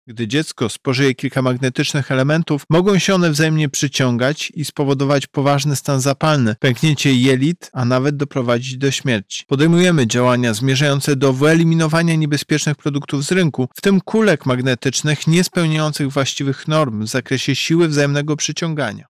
Może się to skończyć tragedią– mówi prezes UOKiK Tomasz Chróstny: